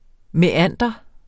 Udtale [ mεˈanˀdʌ ]